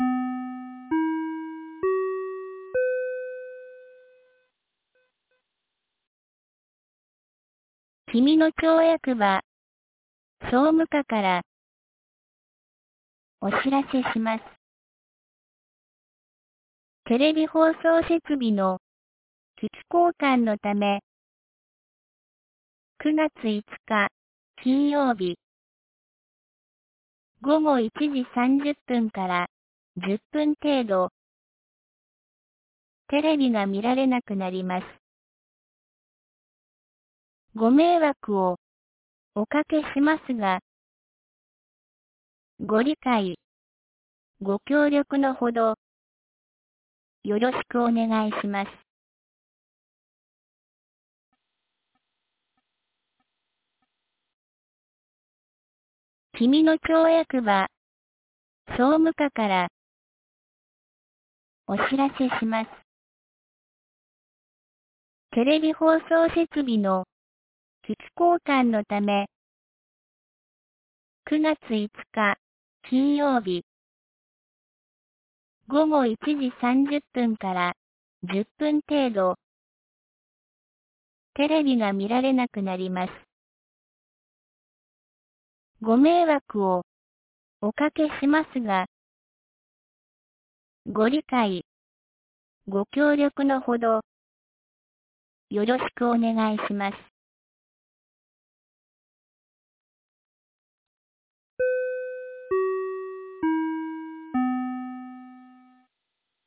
2025年09月03日 17時11分に、紀美野町より長谷毛原地区へ放送がありました。